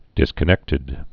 (dĭskə-nĕktĭd)